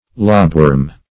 Lobworm \Lob"worm`\, n. (Zool.)